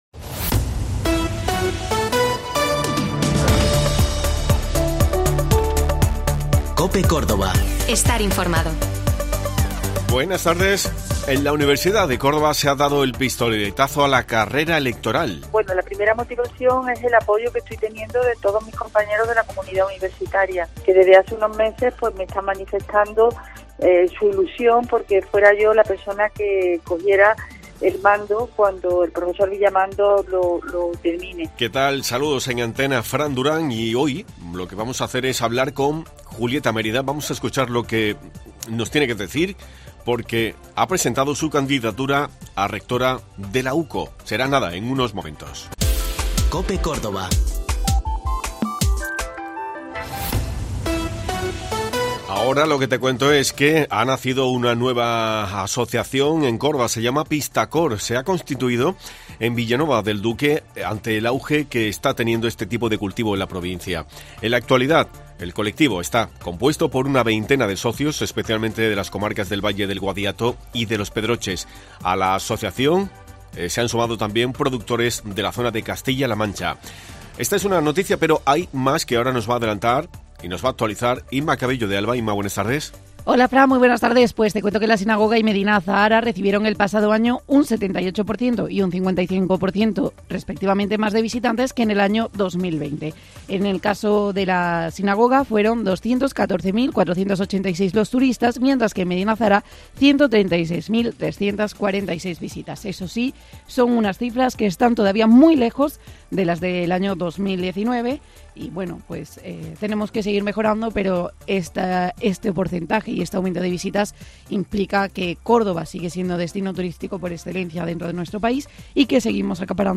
Hoy hemos hablado con ella. Además te hemos contado que ha nacido una nueva asociación en Córdoba bajo el nombre de Pistacor.
LA ACTUALIDAD CADA DÍA Te contamos las últimas noticias de Córdoba y provincia con los reportajes que más te interesan y las mejores entrevistas.